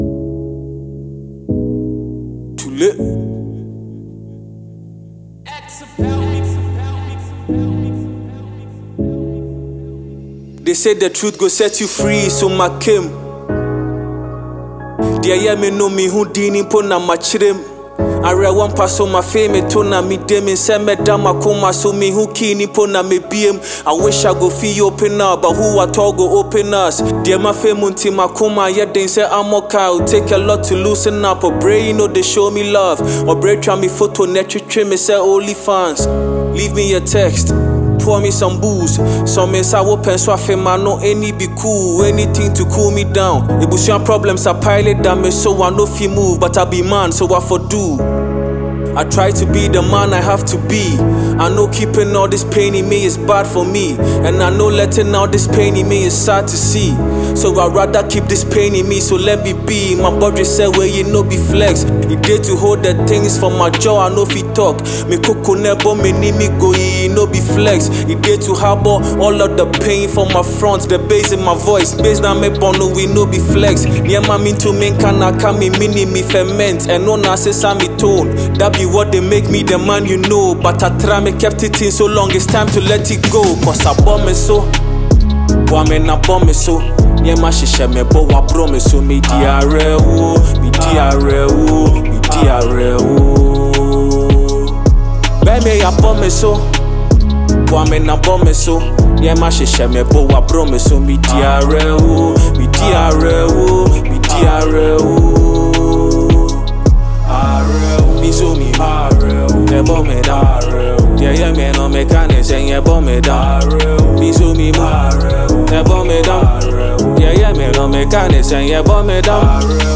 sharp delivery and confident aura